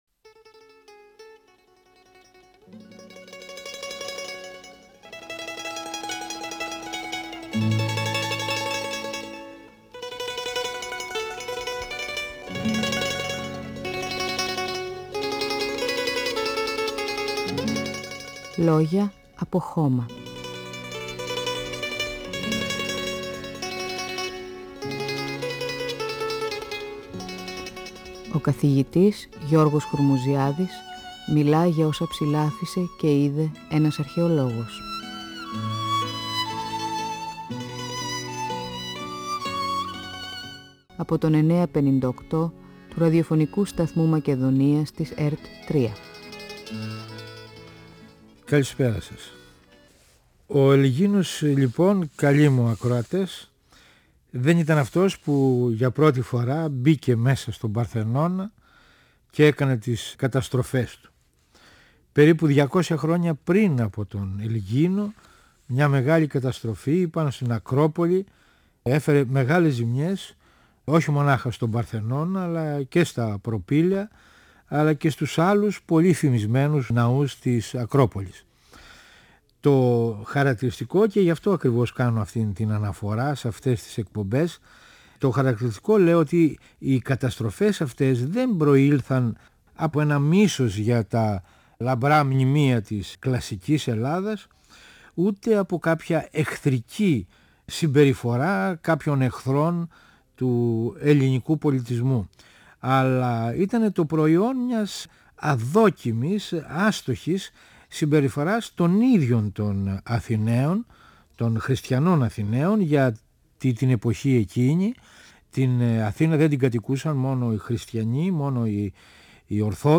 ΦΩΝΕΣ ΑΡΧΕΙΟΥ του 958fm της ΕΡΤ3